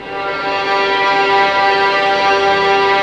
Index of /90_sSampleCDs/Roland L-CD702/VOL-1/STR_Vlns Bow FX/STR_Vls Pont wh%